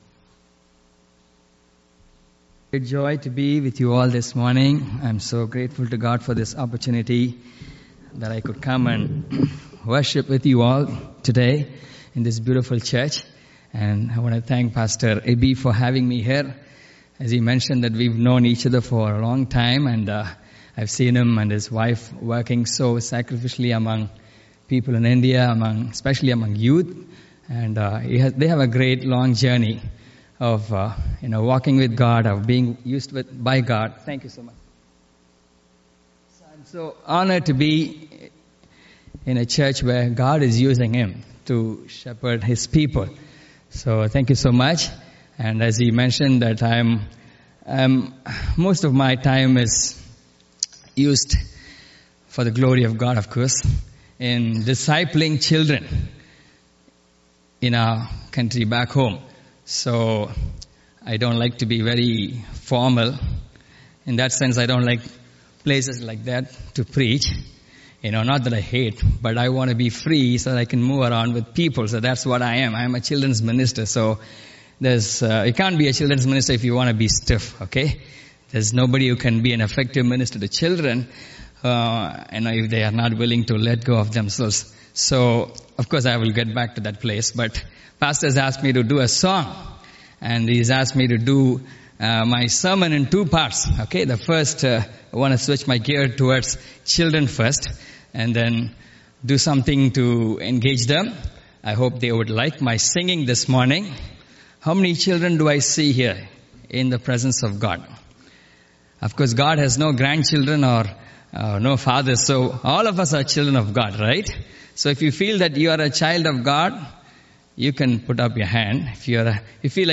Sermon
Sunday Worship Service